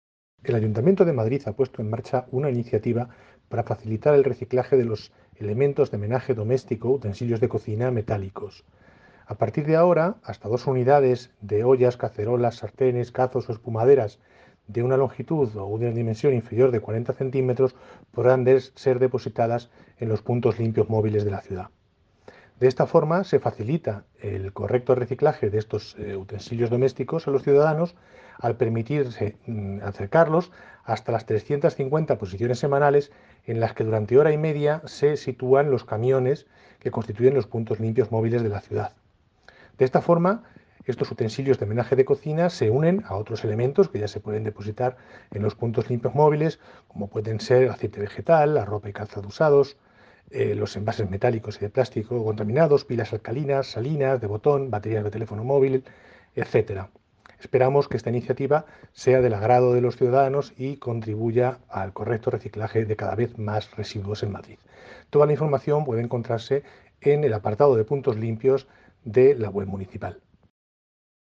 Declaraciones de Víctor Sarabia, Director General de Servicios de Limpieza y Residuos